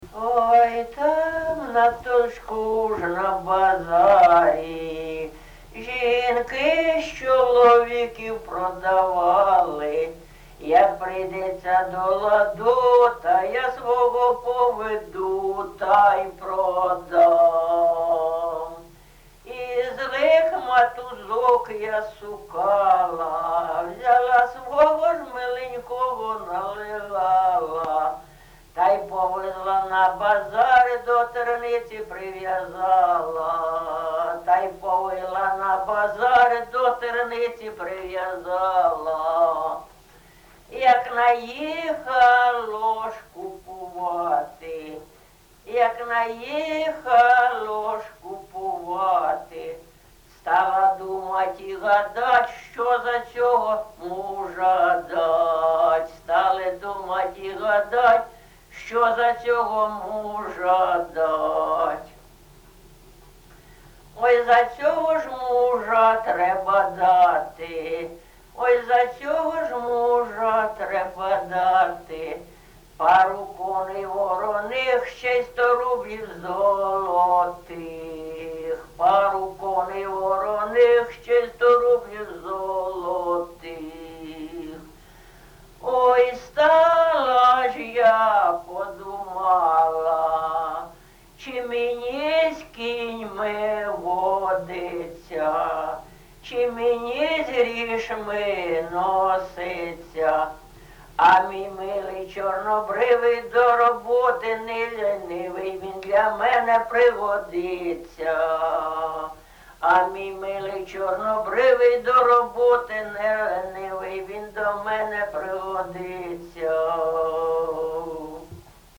ЖанрПісні з особистого та родинного життя, Жартівливі
Місце записус. Софіївка, Краматорський район, Донецька обл., Україна, Слобожанщина